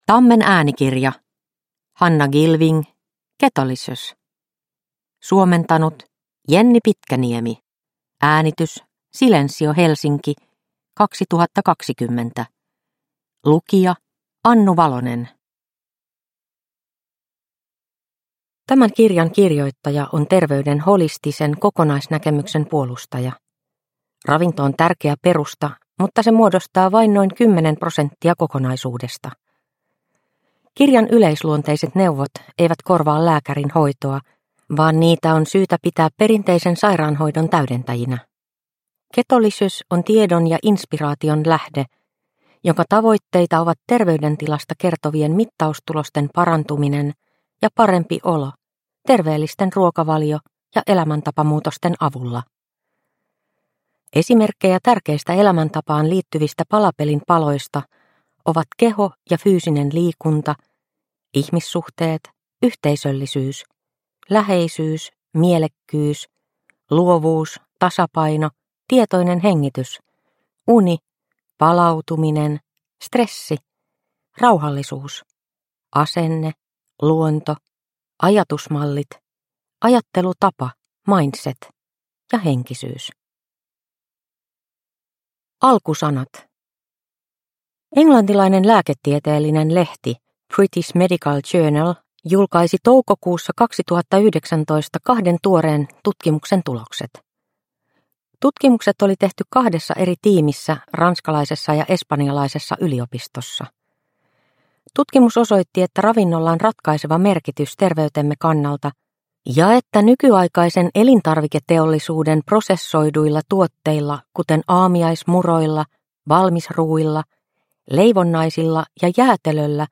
Ketolicious – Ljudbok – Laddas ner